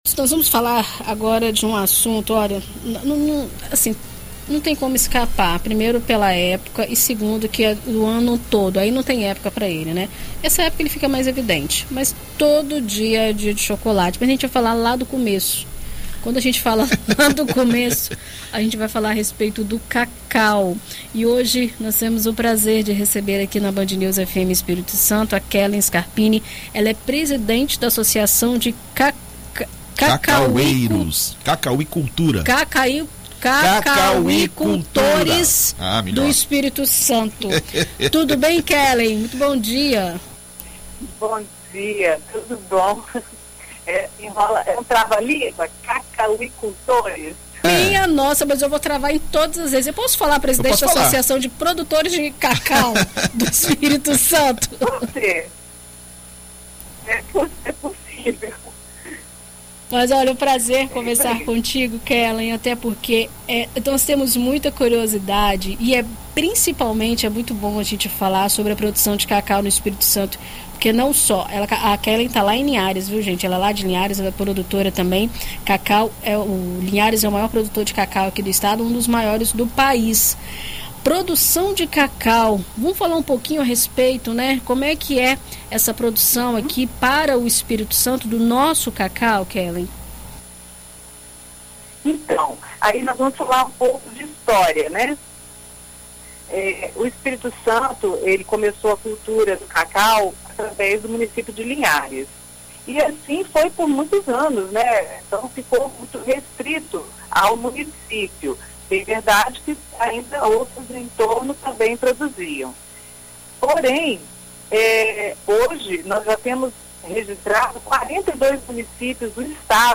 Em entrevista à BandNews FM ES nesta segunda-feira